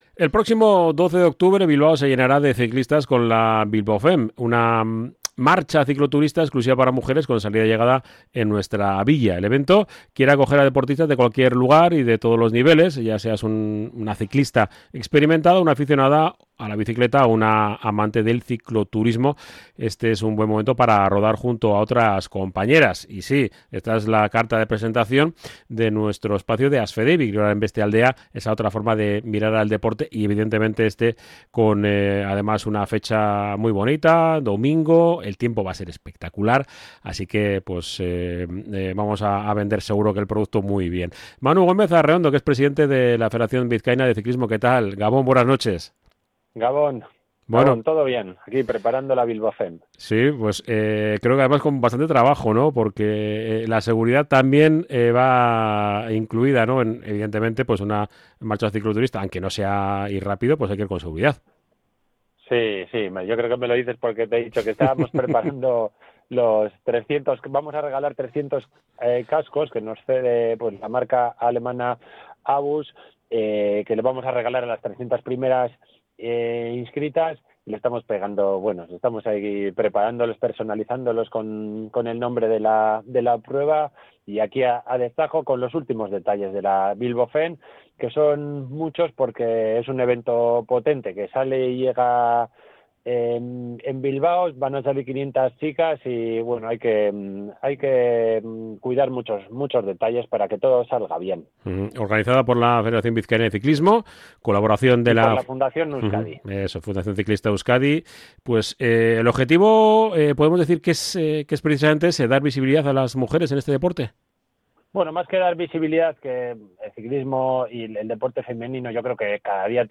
Nuevo ‘ASFEDEBI: La otra cara del deporte / Kirolaren beste aldea’ emitido en el dentro del programa Oye Cómo Va Dark Edition